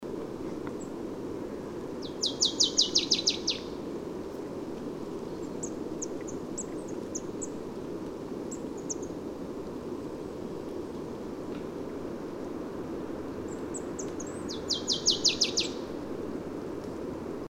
The Neudarss forest provide aplenty of this species so I could hear it’s calls
PFR07498, 130204, Marsh Tit Poecile palustris, song